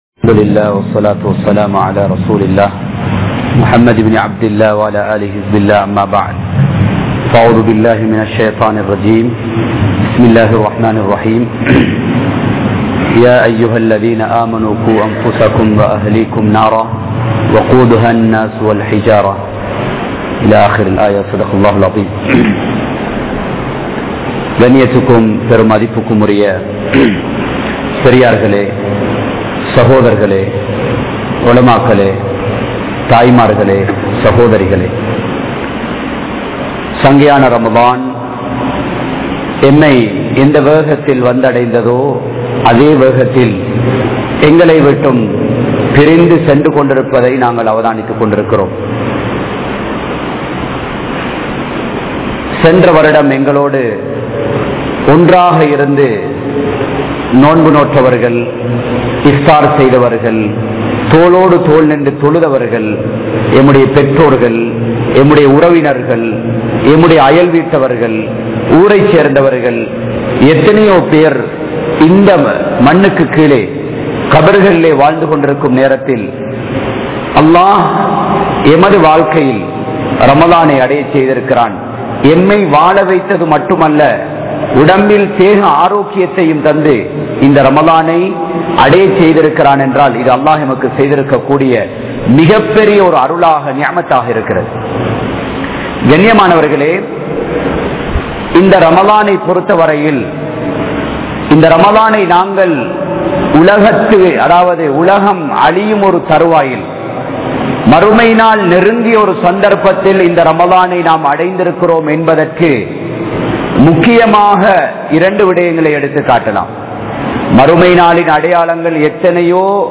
Kodiya Naraham | Audio Bayans | All Ceylon Muslim Youth Community | Addalaichenai
Grand Jumua Masjitth